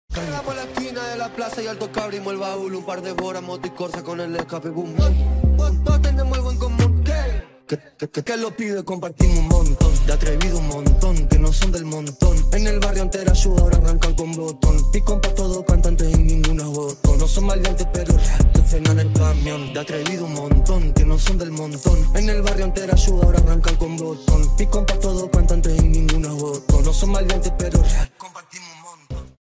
Esto es un MASHUP.